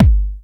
Kick_20.wav